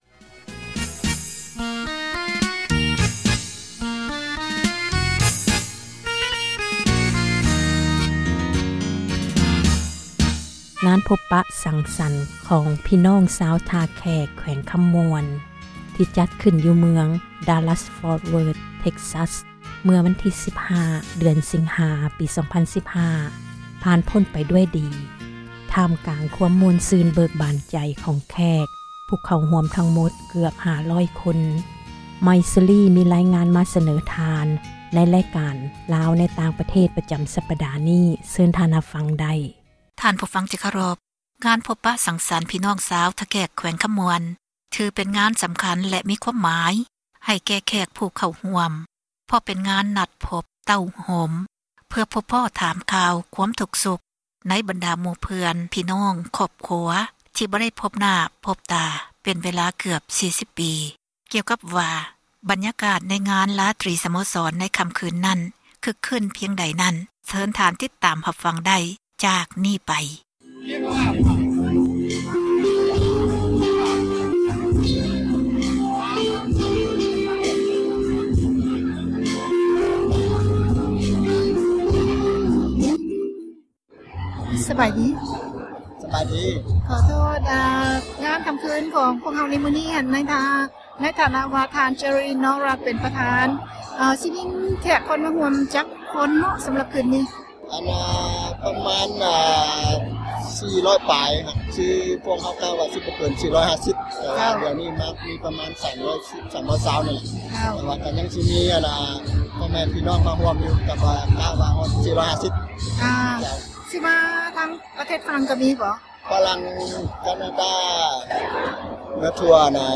ງານ ພົບປະ ສັງສັນ ຂອງ ພີ່ນ້ອງ ຊາວ ທ່າແຂກ ແຂວງ ຄໍາມ່ວນ ທີ່ ຈັດຂຶ້ນ ຢູ່ ເມືອງ Dallas Forth Word, Texas ເມື່ອ ວັນທີ 15 ສິງຫາ 2015, ຜ່ານ ພົ້ນໄປ ຖ້າມກາງ ຄວາມ ມ່ວນ ຊື່ນ ເບີກບານ ໃຈ ຂອງ ທຸກຄົນ ທີ່ ເຂົ້າຮ່ວມ ທັງໝົດ ເກືອບ 500 ຄົນ.